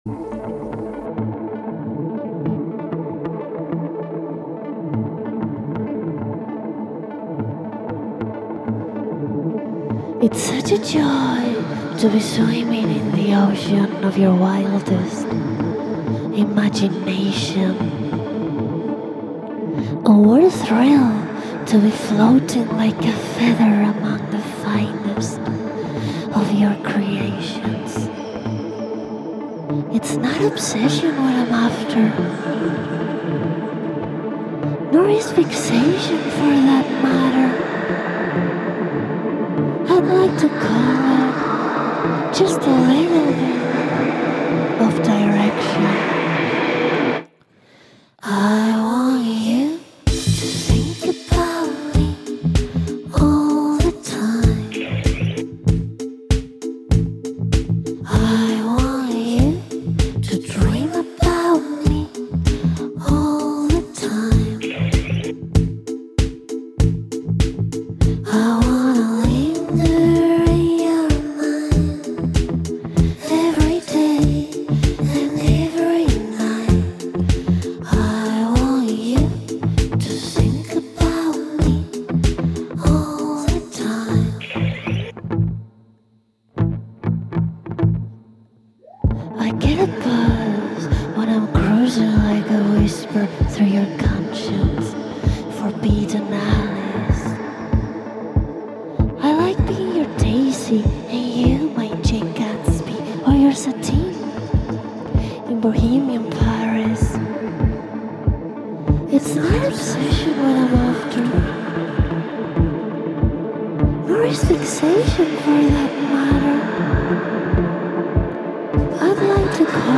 a Spanish duo
vocalist